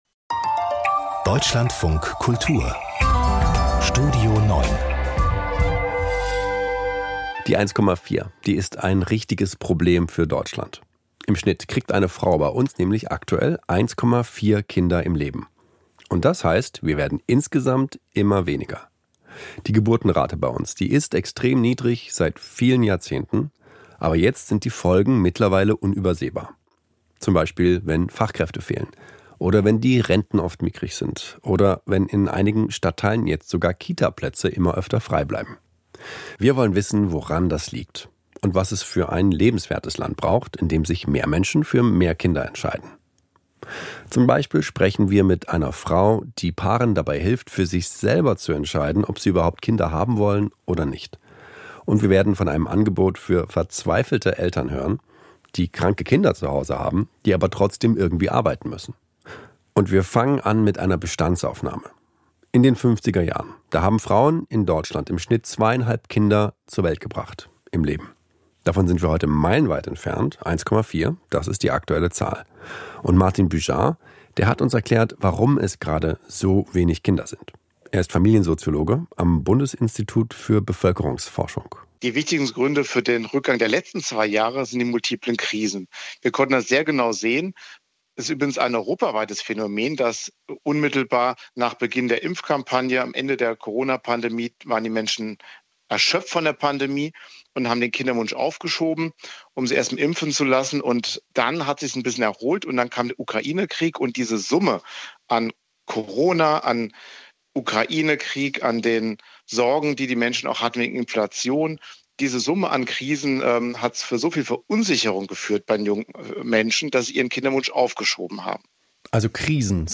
In einer Interview-Reihe erklärt ein Soziologe, was sich ändern muss. Seniorinnen, die Familien ehrenamtlich helfen sowie eine Paar-Beraterin kommen zu Wort.